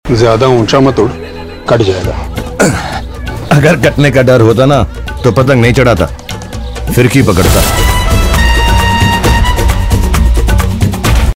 Bollywood Dialogue Tones